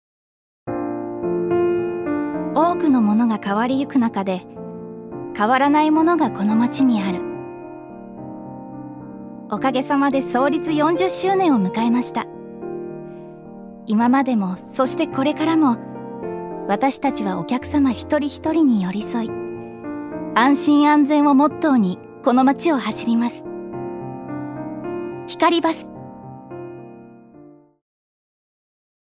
預かり：女性
ナレーション２